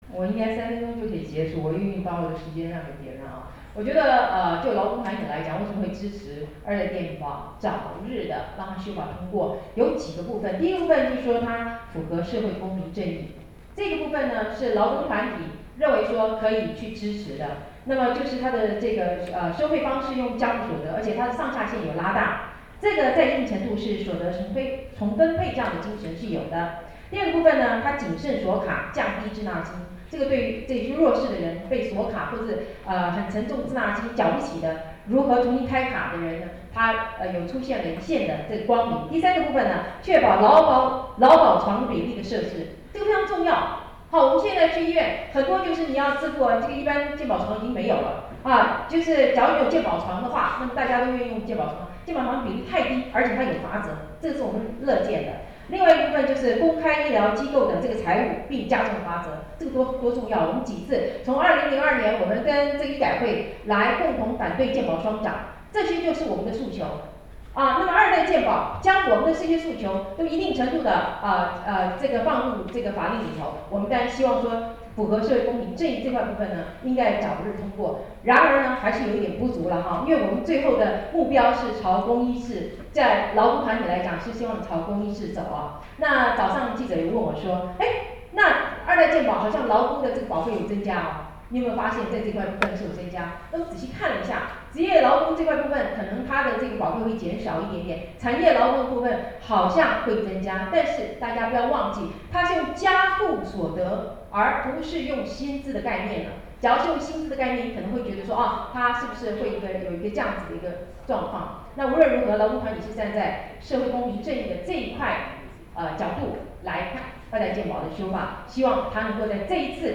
「健保改革不能退怯  修法時機必須掌握」記者會
發言錄音檔